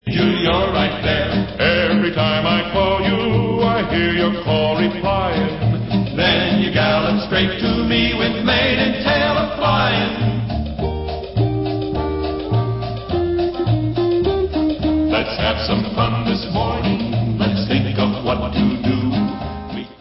Western soundtracks